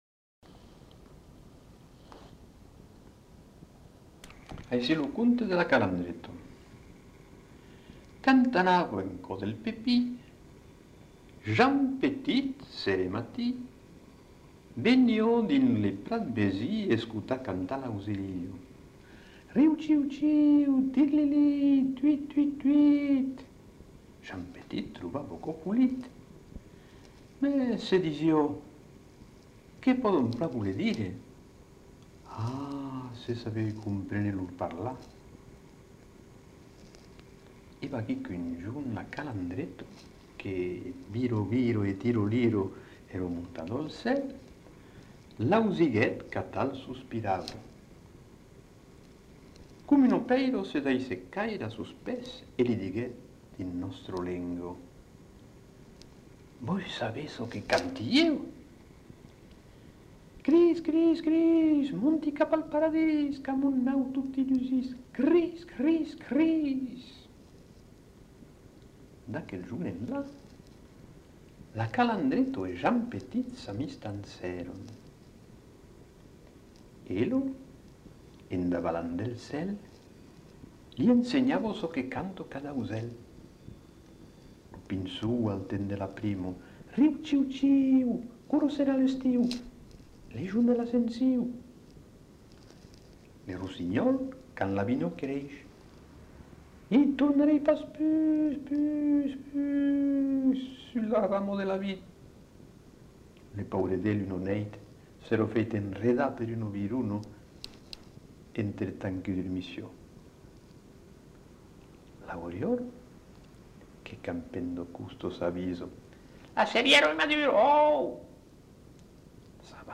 Genre : conte-légende-récit
Effectif : 1
Type de voix : voix d'homme
Production du son : lu
Notes consultables : Conte avec mimologismes.